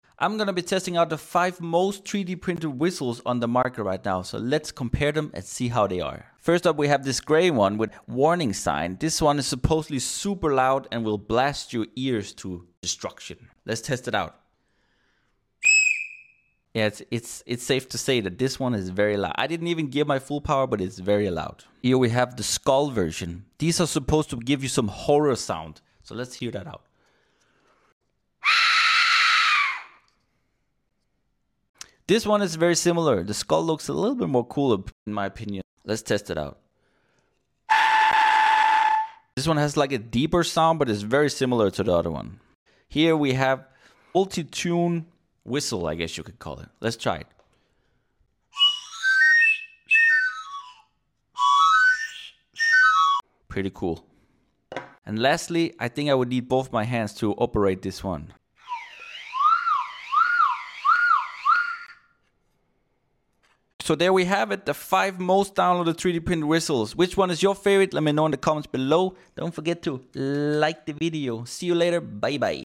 Top 5 Most Downlaoded Whistles Sound Effects Free Download